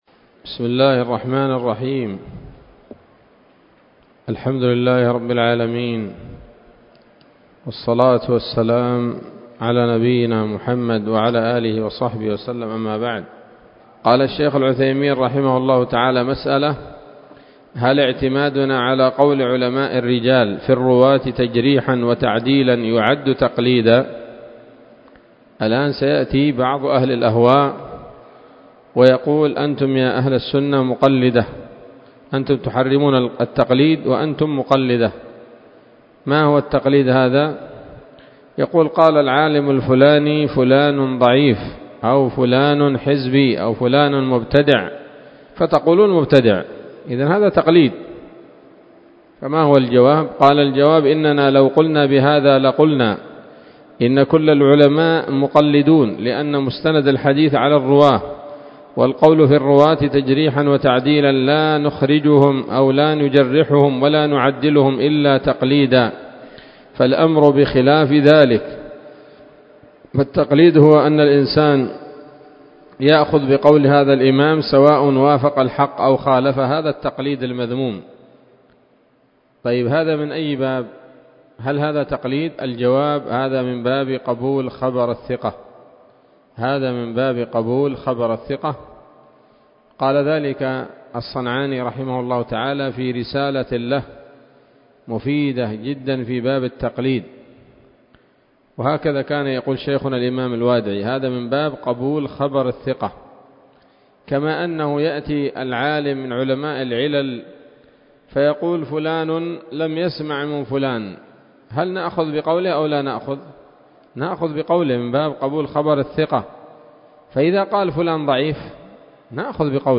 الدرس الثامن والستون من شرح نظم الورقات للعلامة العثيمين رحمه الله تعالى